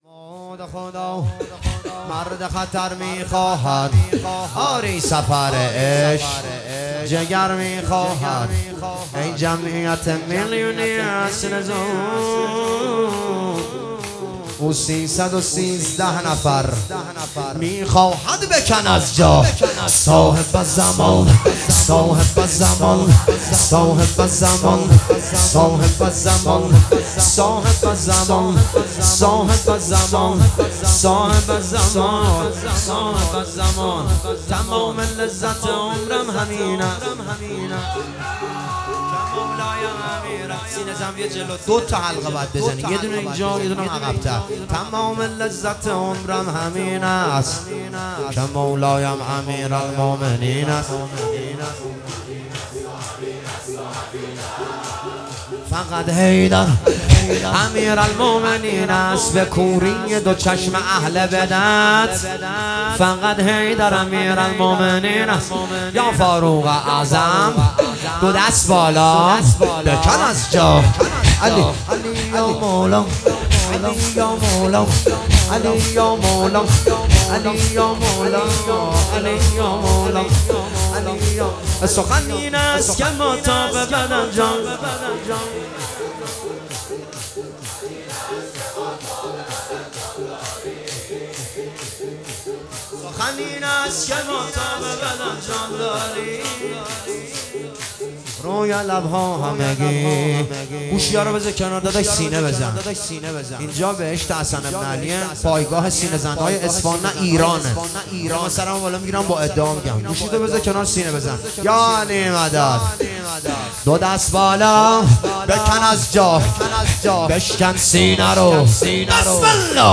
جلسه هفتگی اردیبهشت 1404